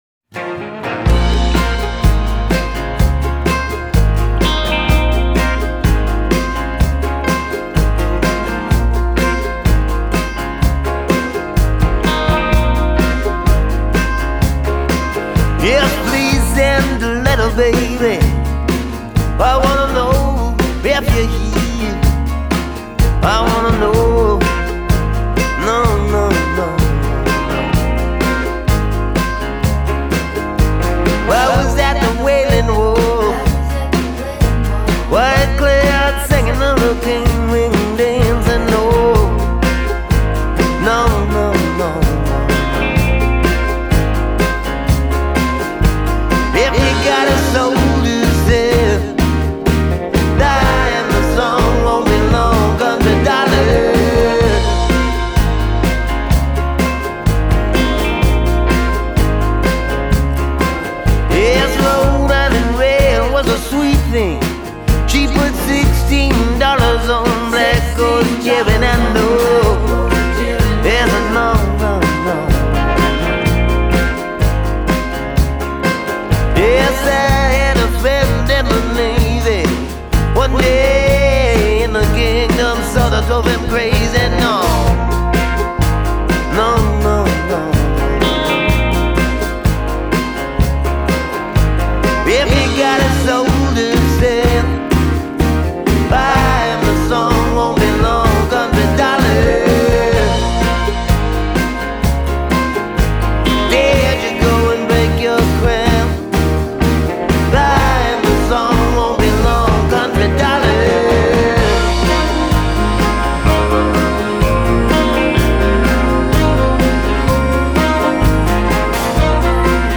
warm, soulful vibe
animated folk